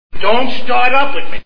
All in the Family TV Show Sound Bites